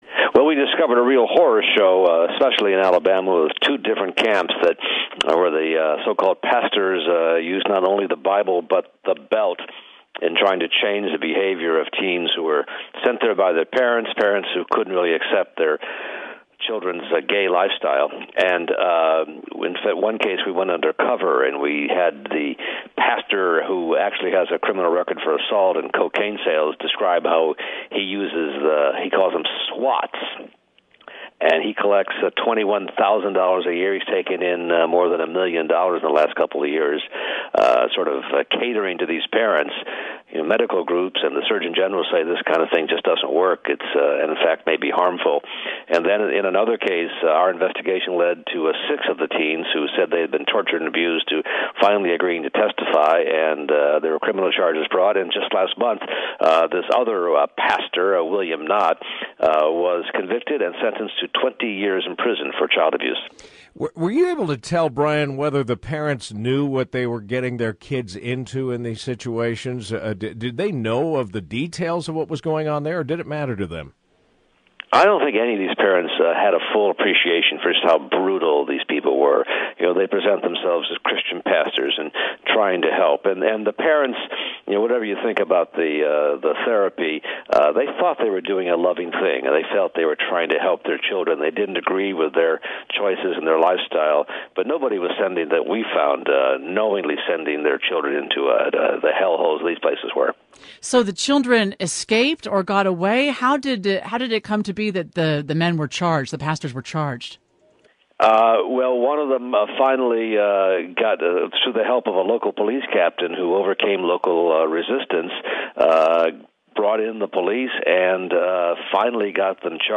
ABC News investigative reporter Brian Ross special report on Christian youth camps where widespread abuse is being alleged